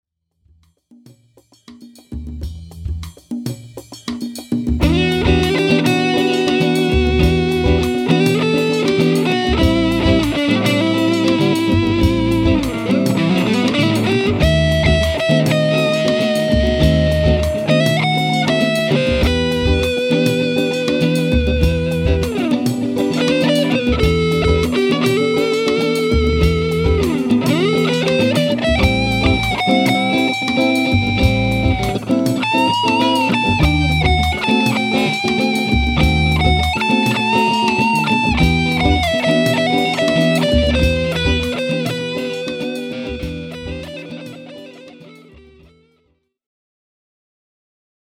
So this evening, I went back into my home studio, pulled the SH575 out of its case, and just started noodling around to find out where the sweet spot was with the guitar.
Once I lightened up my touch, I was able to get some really stinging sustain out of it, and for that, I decided to give it a higher rating. Here’s a quick clip I made that demonstrates the nice sustain:
I added some overdrive with my trusty Tube Screamer, but it was pretty light. I wanted the guitar to do most of the work, and I added just a touch of reverb to create a little ambiance in the tone. Excuse my little mistakes in playing… 🙂